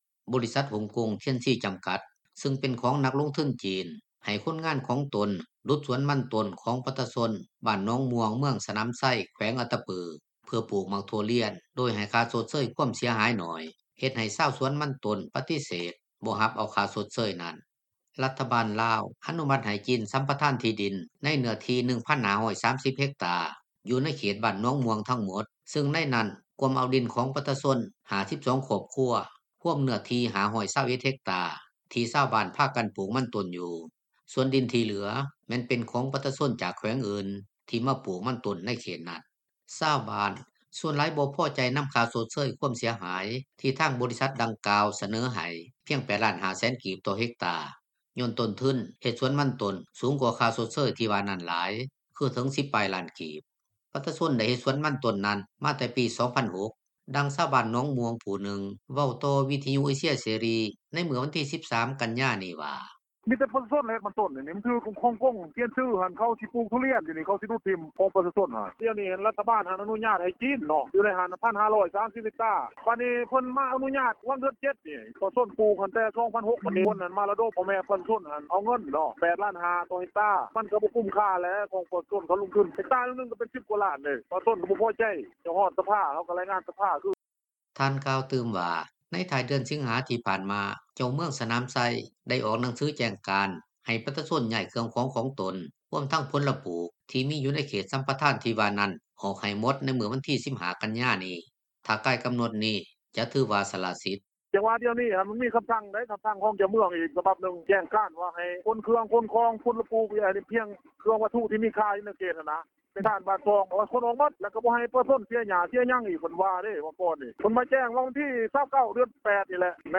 ກ່ຽວກັບເລື່ອງທີ່ວ່ານີ້ ເຈົ້າໜ້າທີ່ທີ່ກ່ຽວຂ້ອງ ຢູ່ແຂວງອັດຕະປື ນາງນຶ່ງເວົ້າວ່າ ປັດຈຸບັນ ໂຄງການປູກຖົ່ວລຽນທີ່ວ່ານີ້ ຍັງຢູ່ໃນຂັ້ນຕອນຂອງການປະຕິບັດ ຢູ່ໃນສ່ວນ ທີ່ໄດ້ຮັບຄວາມເປັນເອກກະພາບ ຈາກປະຊາຊົນ, ແຕ່ສ່ວນໃດ ທີ່ຍັງບໍ່ທັນເປັນເອກກພາບກັນ ກໍຕ້ອງໄດ້ສືບຕໍ່ແກ້ໄຂ ດັ່ງທີ່ນາງເວົ້າວ່າ:
ໃນຂະນະດຽວກັນ ຊາວບ້ານຢູ່ເມືອງສະໜາມໄຊ ນາງນຶ່ງກໍເວົ້າວ່າ ປັດຈຸບັນ ສວນຖົ່ວລຽນ ໃນເຂດບ້ານໜອງມ່ວງ ທີ່ວ່າຈະບຸກເບີກນັ້ນ ຍັງບໍ່ທັນໄດ້ບຸກເບີກ ແລະບໍ່ທັນໄດ້ປູກເທື່ອ: